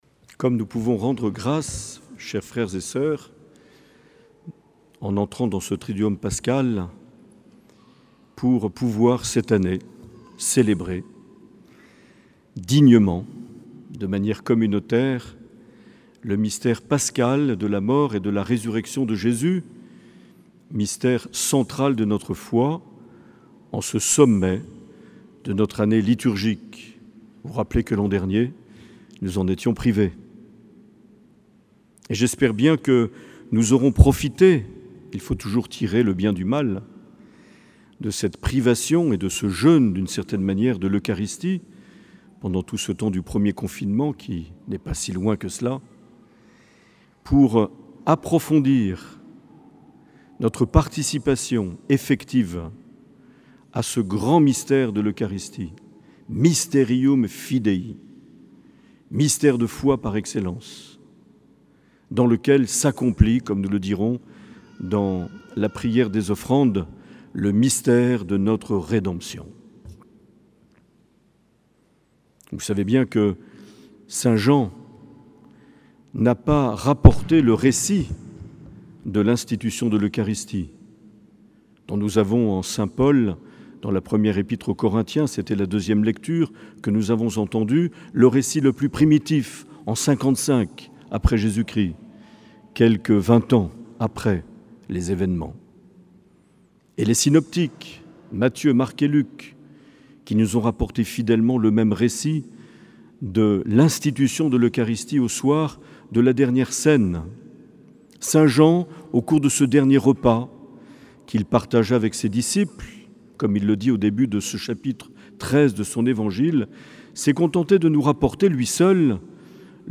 1er avril 2021 - Cathédrale de Bayonne - Jeudi Saint
Les Homélies
Une émission présentée par Monseigneur Marc Aillet